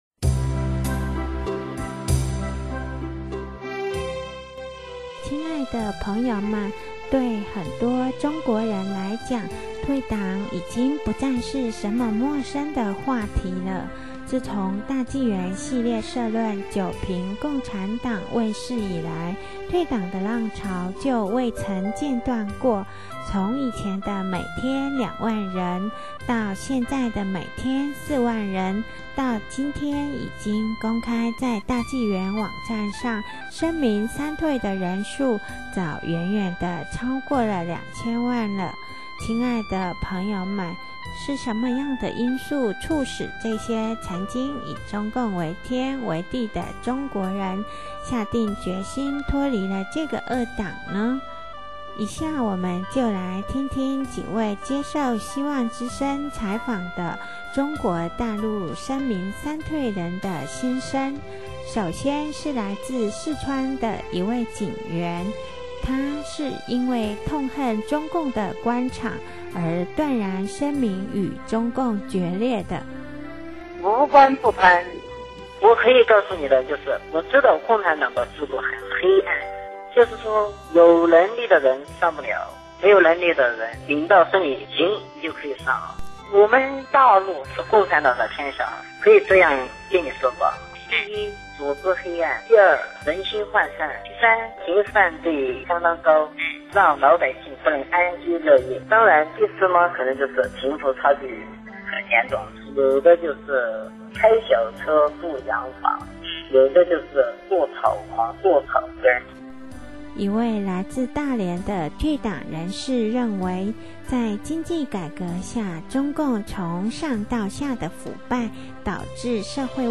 首先是来自四川的一位警员。
另一位来自黑龙江的退党人士称退出中共，其实也是为了要挽救那些至今仍沉醉在中共谎言中的人。